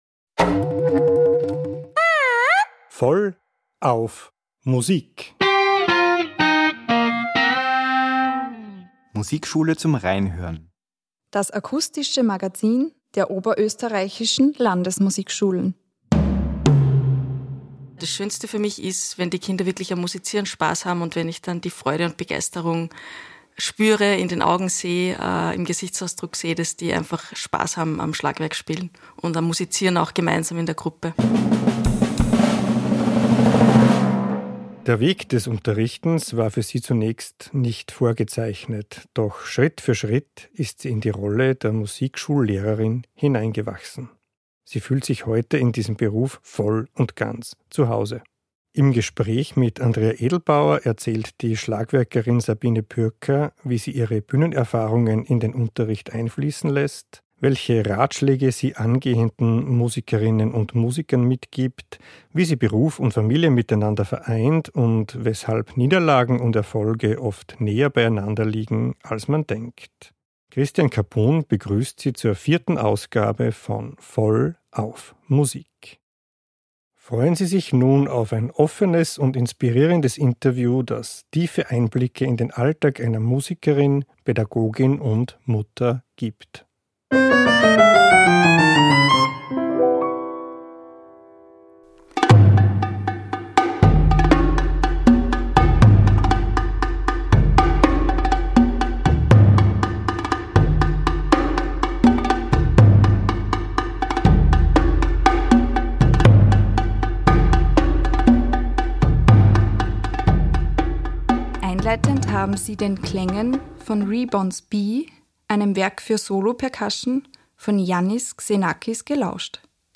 Ein offenes und inspirierendes Interview, das tiefe Einblicke in den Alltag einer Musikerin und Pädagogin gibt.